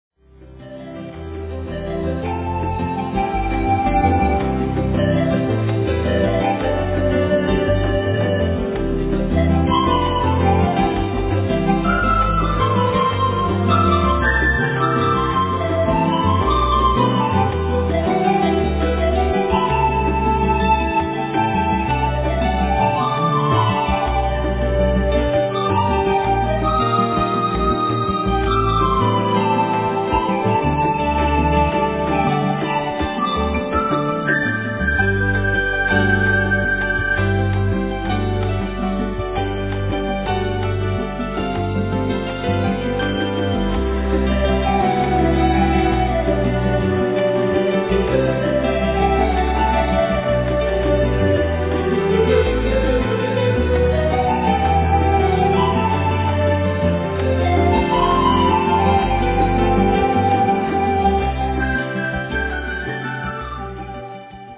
keyboards, vocals
4 strings bass, 6 strings bass, fretless bass
tenor + soprano sax, quena, flute,
drums, percussions